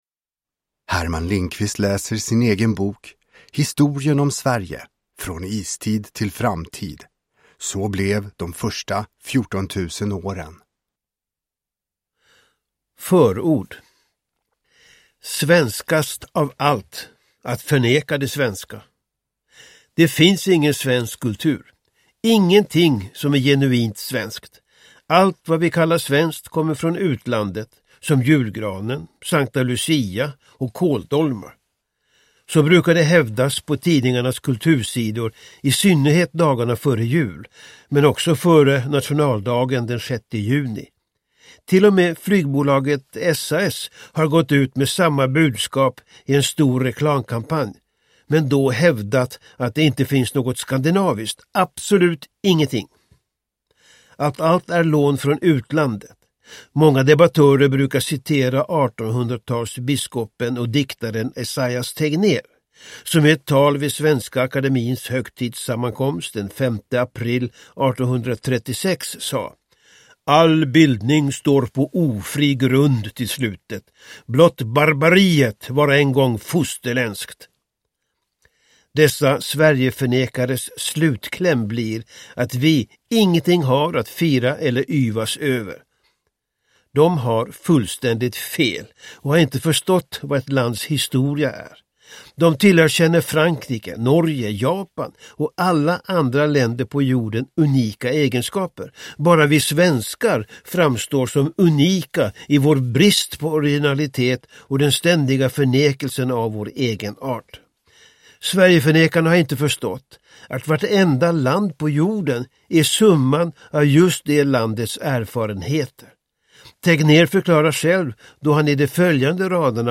Historien om Sverige : från istid till framtid: så blev de första 14000 åren – Ljudbok
Uppläsare: Herman Lindqvist